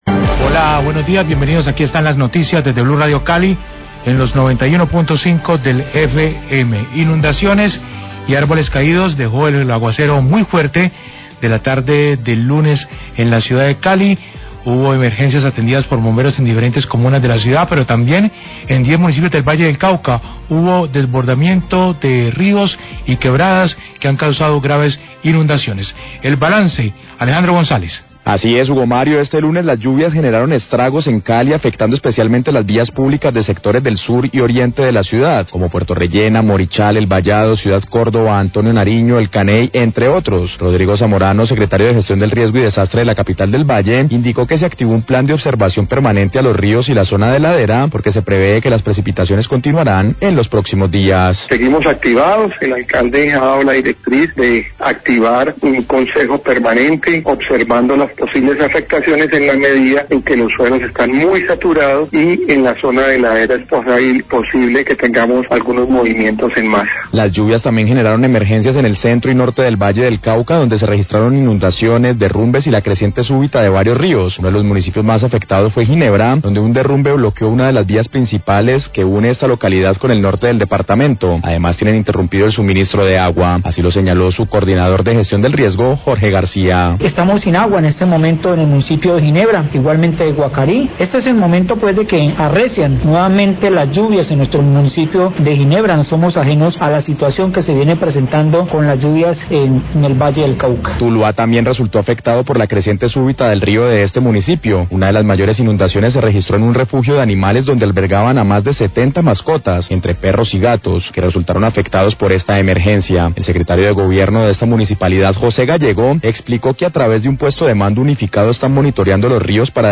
Radio
Balance del Secretario de Gestión del Riesgo de Cali, Rodrigo Zamorano,  sobre las afectaciones por las fuertes lluvias caídas en la ciudad. También hablan funcionarios de Ginebra y Tuluá sobre las crecientes súbitas y derrumbes por las lluvias.